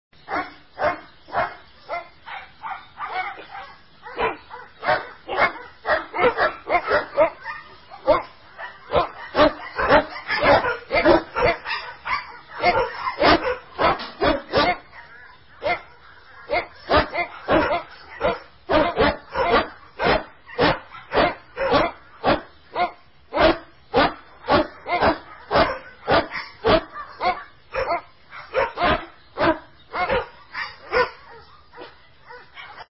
3D звук , слушать только в наушниках (иначе эффекта ни будет ) , желательно в компьютерных , итак включаем , закрываем глаза , расслабляемся , слушаем !
3D_Dogs.mp3.32.mp3